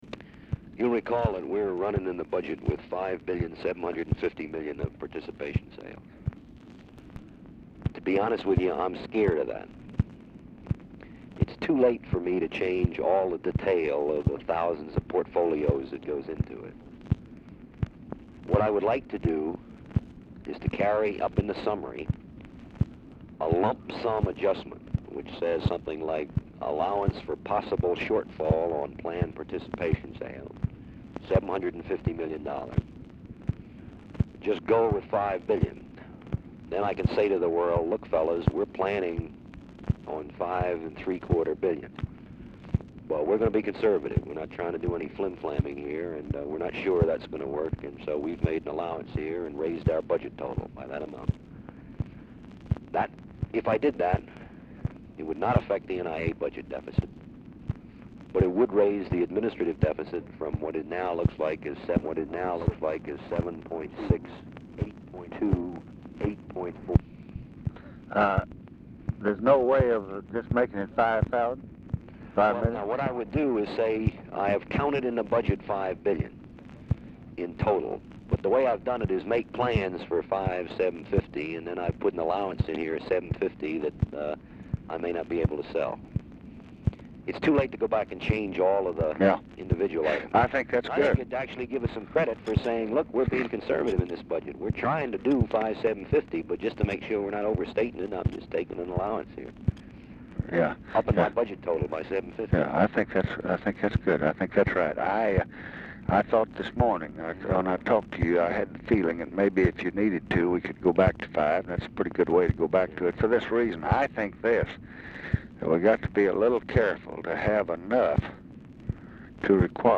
Telephone conversation # 11315, sound recording, LBJ and CHARLES SCHULTZE, 1/6/1967, 5:05PM
"RE BUDGET MATTERS"; RECORDING STARTS AFTER CONVERSATION HAS BEGUN; CONTINUES ON NEXT RECORDING
Format Dictation belt
Location Of Speaker 1 Mansion, White House, Washington, DC